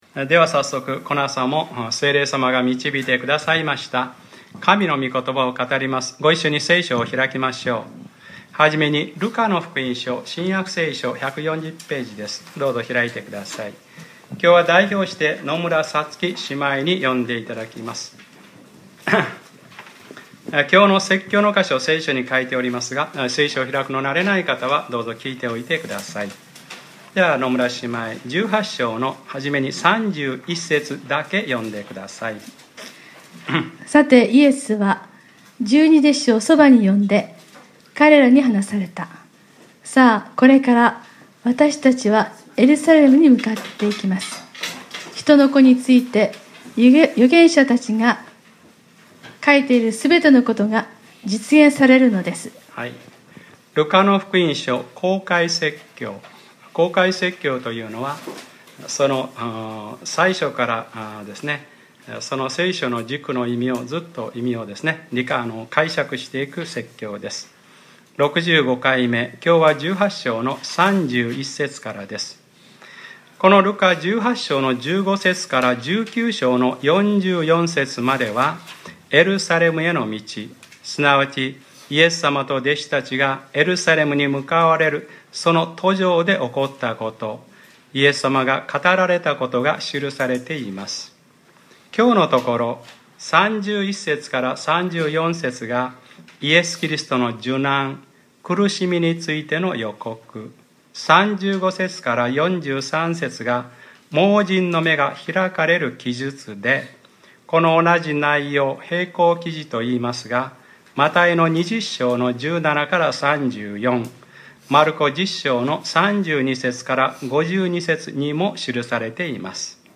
2015年08月02日（日）礼拝説教 『ルカｰ６５：主よ。目が見えるようになることです。』